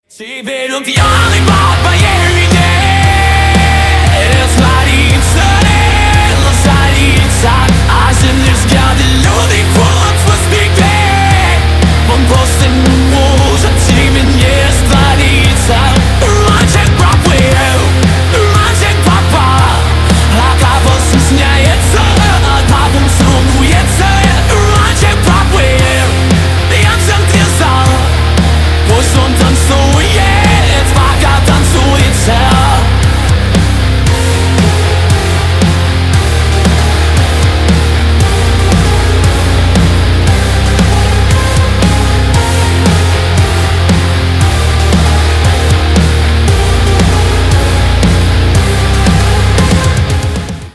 Рок рингтоны